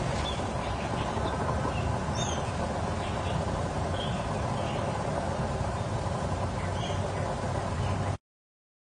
野外采集斑文鸟叫声